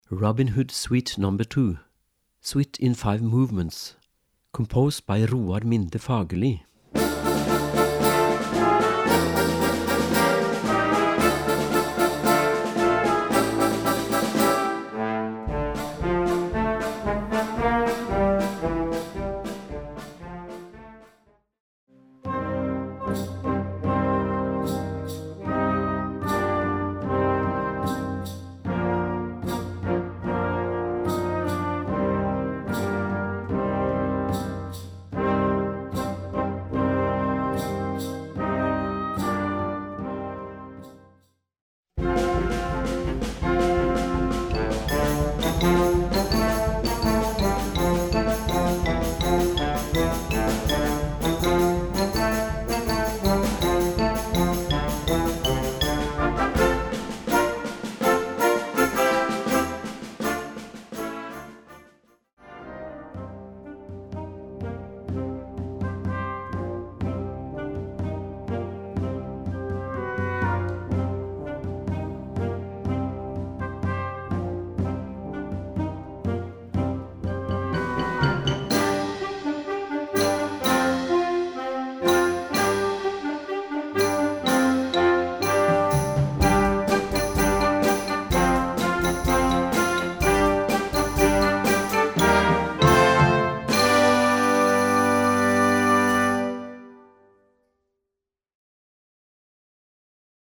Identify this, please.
Gattung: für Blasorchester Besetzung: Blasorchester